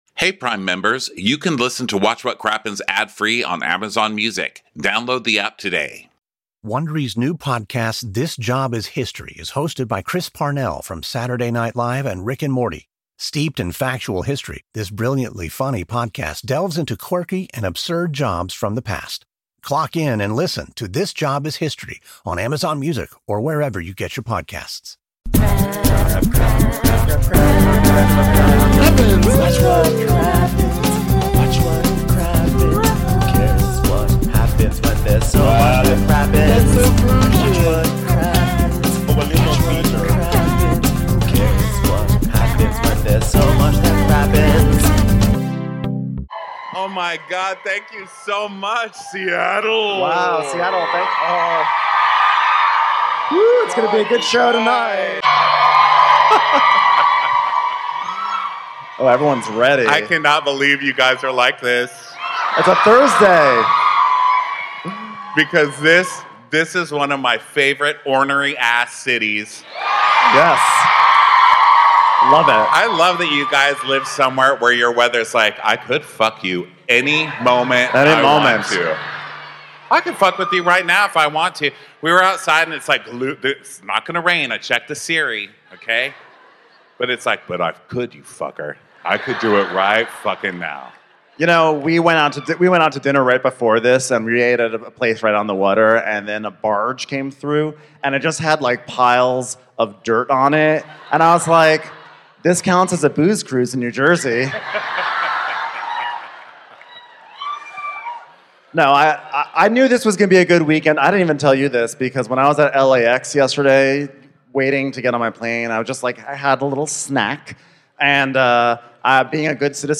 We're live at the Neptune Theater in Seattle for this week's Real Housewives of New Jersey recap. There's a party at the Fuda's shore house, and Luis creeps us out even further with a story about his latest night time attire.